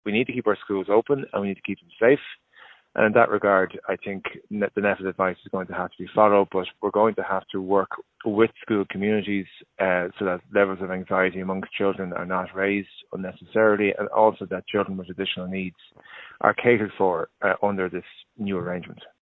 Labour’s Education Spokesperson, Aodhan O’Riordan, says sensitivity is needed: